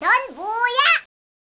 효과음